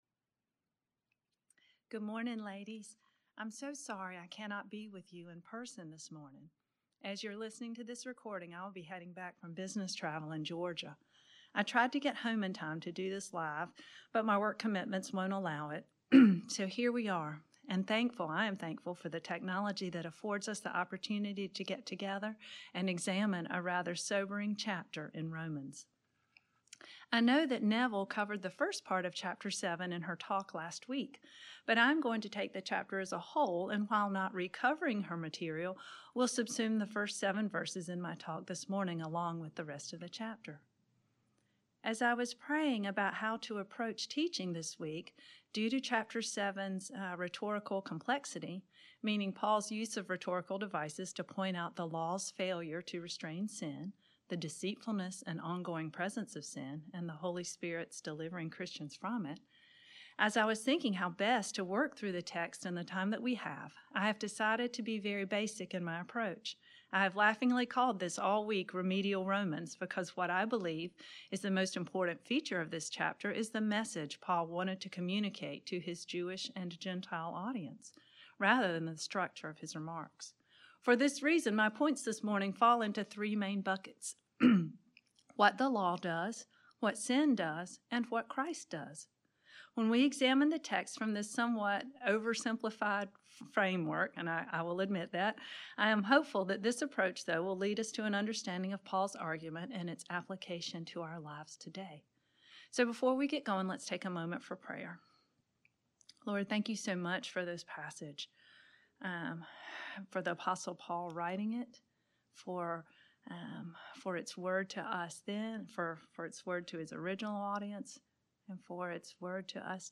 Women of the Word Wednesday Teaching Lesson 7:7-25 Nov 14 2023 | 00:27:13 1x 00:00 / 00:27:13 Subscribe Share RSS Feed Share Link Embed